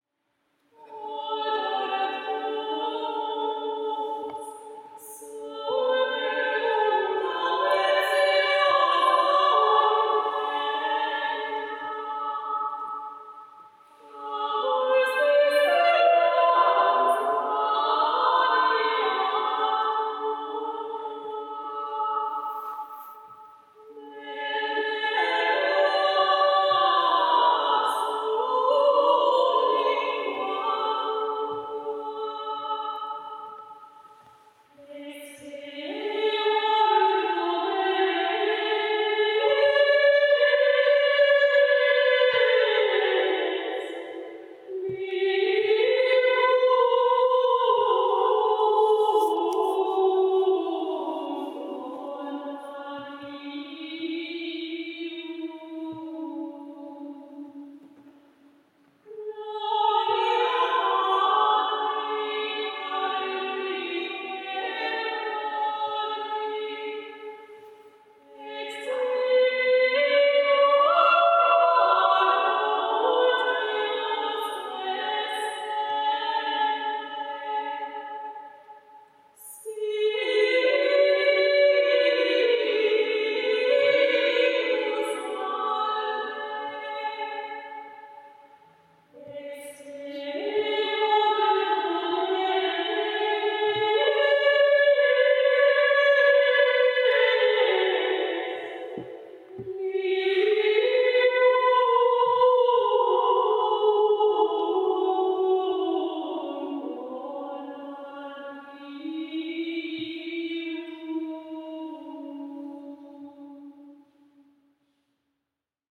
Koncer pasyjny na Kamionku - reportaże i wspomnienia
Każda z wykonawczyń miała sposobność solowego zaprezentowania swojego kunsztu wokalnego.
Każdy głos z osobna i w wielogłosie ukazał nie tylko profesjonalizm śpiewaczy, ale również znajomość specyfiki wokalistyki dawnych wieków.
In lectulo meo – antyfona z XV wieku (Bazylea, Szwajcaria),